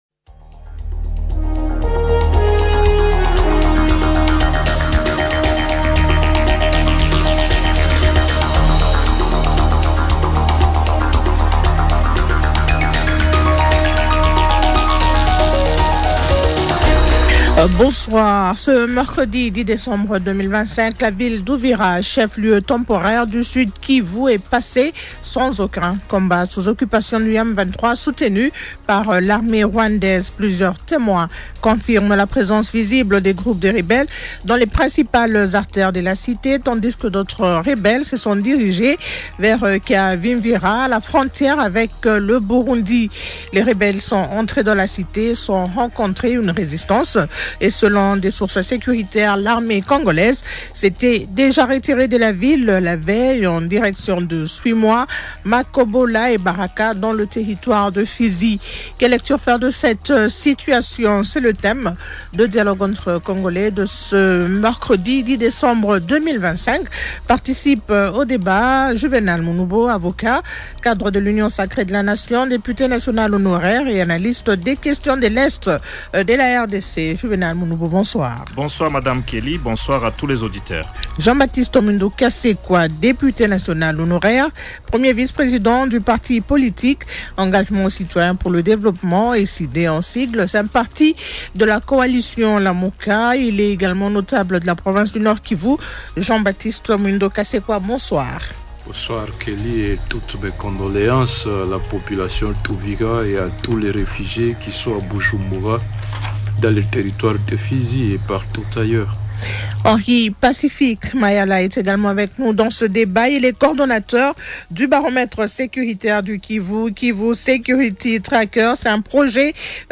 Il est député national honoraire et analyste des questions de l’Est de la RDC.